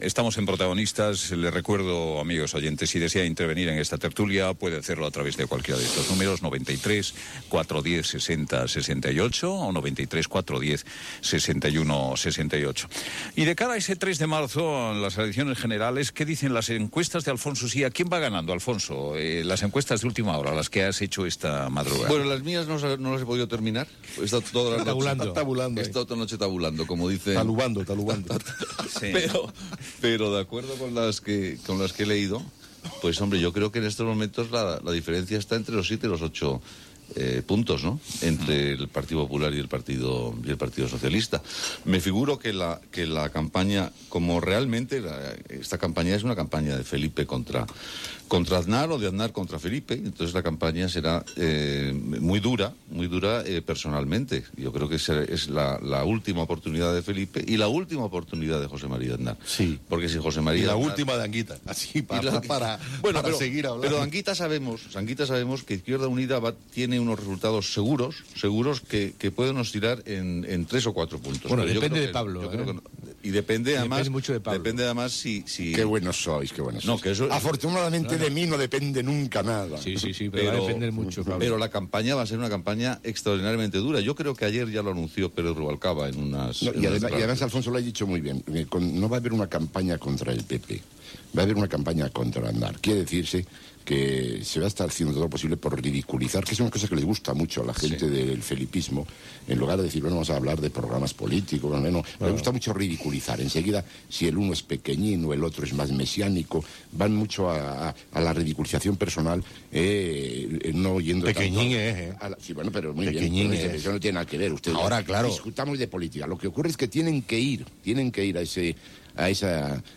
Telèfons de participació, les eleccions generals espanyoles, estudi sobre els jutges i fiscals al País Basc. Espai publicitari, trucades de l'audiència per opinar
Info-entreteniment